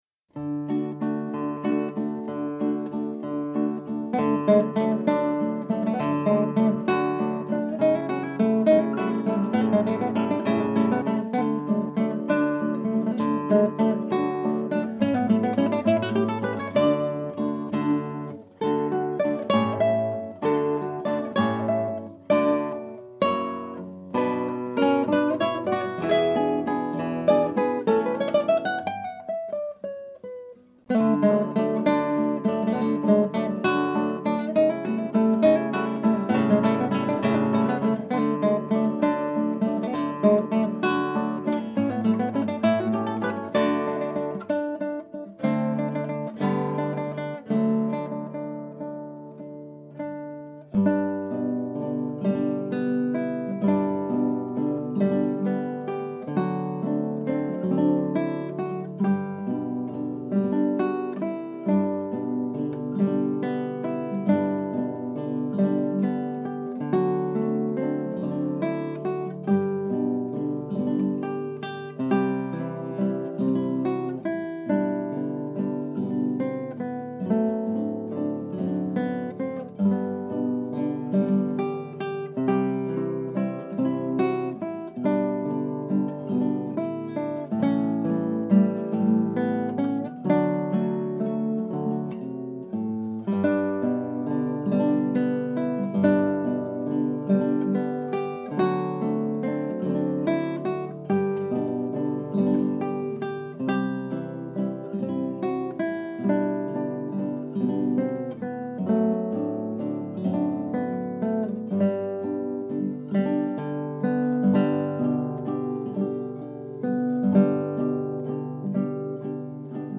Scraps from the Operas arranged for Two Guitars
Composer: Carl Maria von Weber
Scrap 1: Allegro.
Scrap 2 (0:51): Andante con moto.